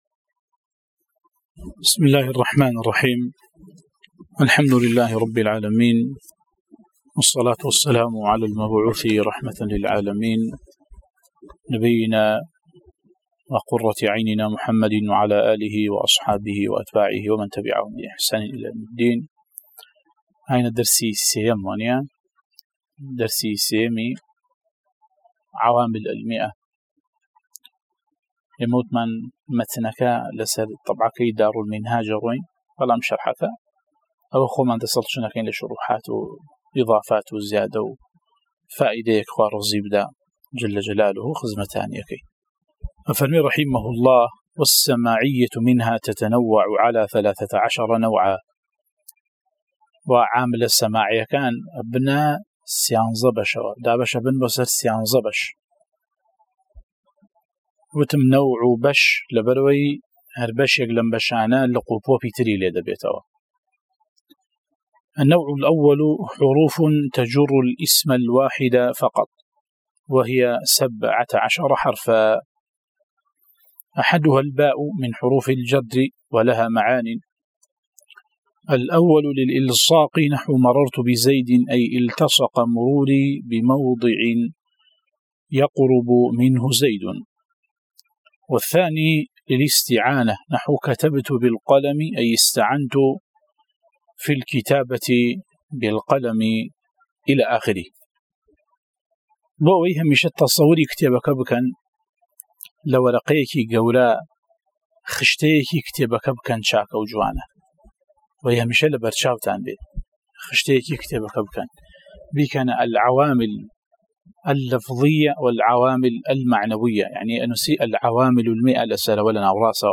03 ـ شەرحی العوامل المائة، (عوامل الجرجانی) (نوێ) وانەی دەنگی: